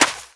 STEPS Sand, Run 01.wav